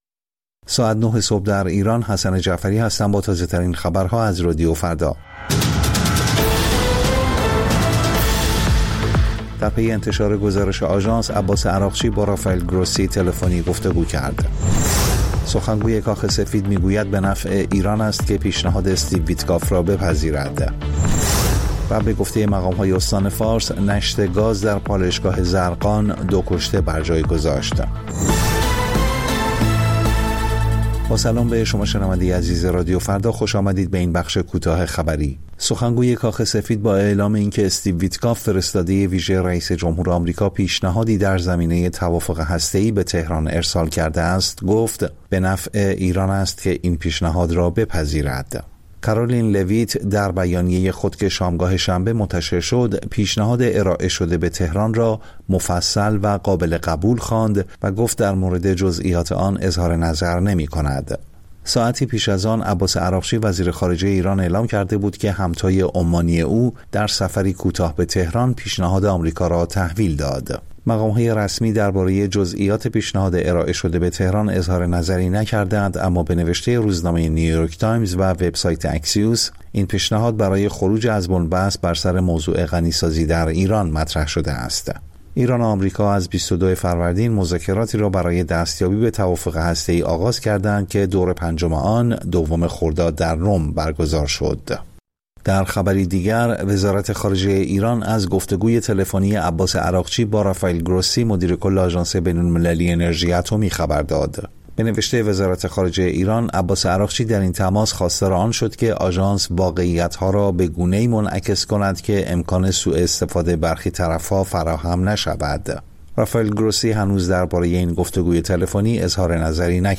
سرخط خبرها ۹:۰۰